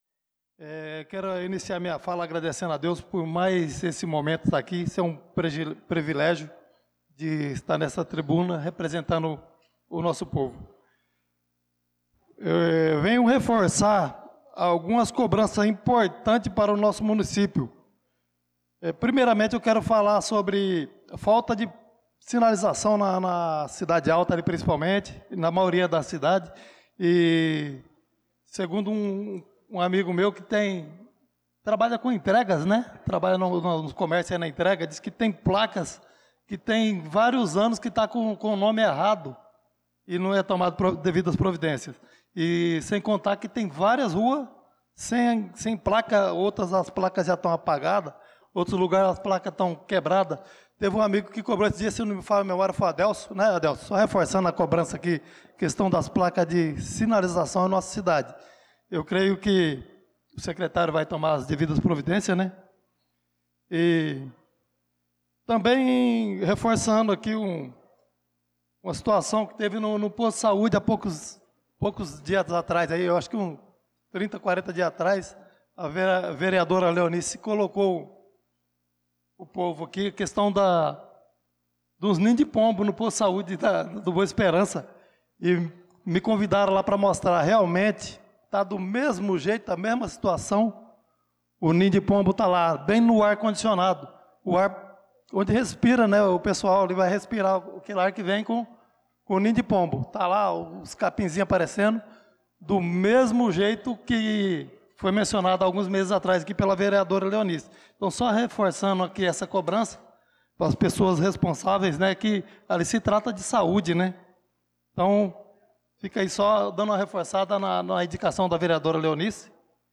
Pronunciamento do vereador Chicão Motocross na Sessão Ordinária do dia 16/06/2025.